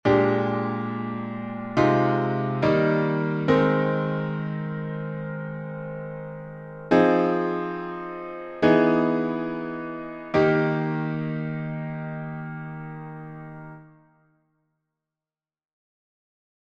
How many parts: 4
Type: Barbershop
All Parts mix: